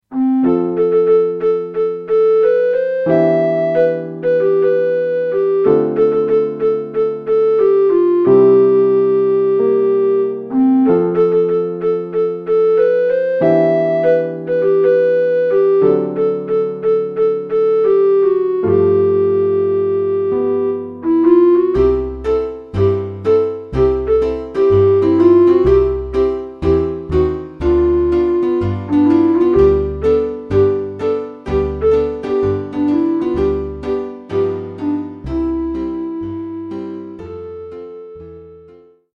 (Backing + Melody)